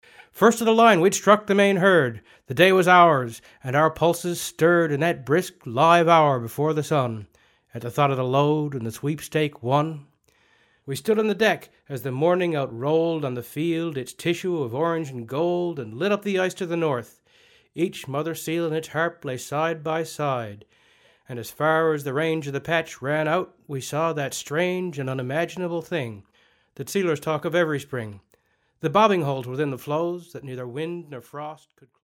- dramatic recitation.